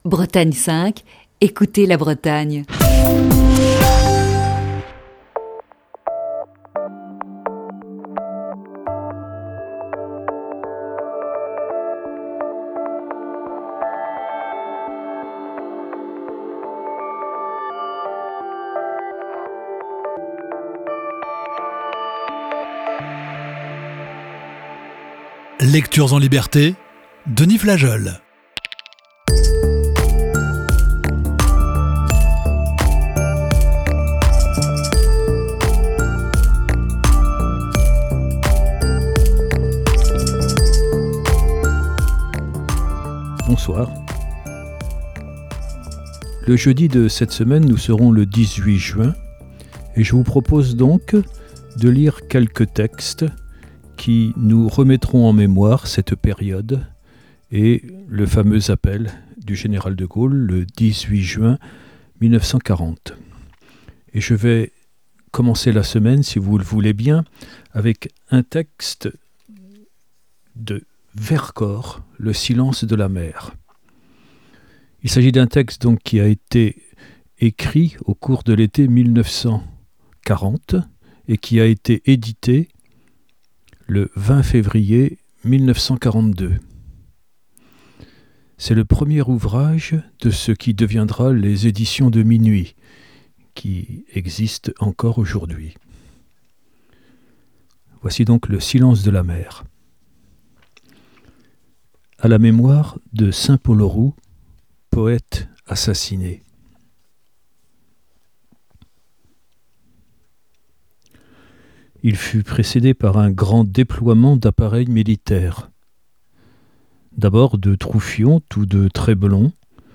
Émission du 15 juin 2020.